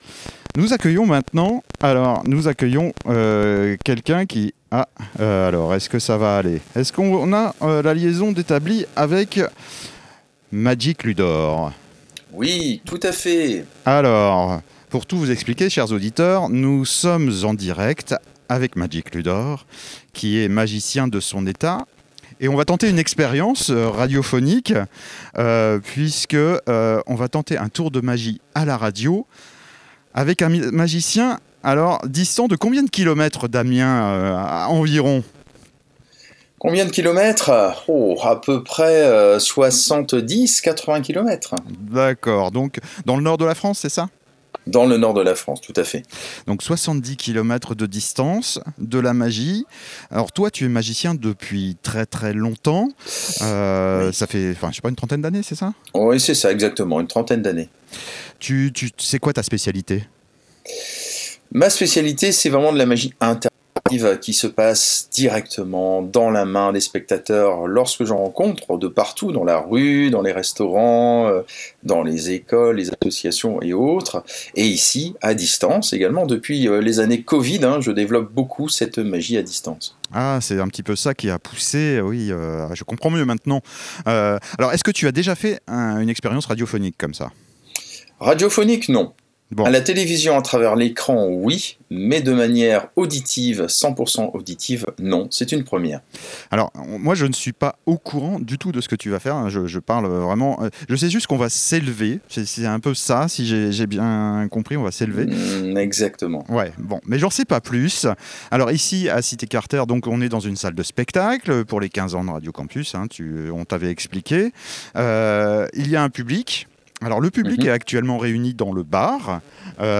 Les 16 et 17 mai 2025, Radio Campus Amiens a continué la fête des 15 ans d’émissions sur les ondes avec le soutien inestimable de toute l’équipe de Cité Carter qui nous a accueilli dans leurs locaux et a assuré… Lire l'article